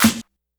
Snare_24.wav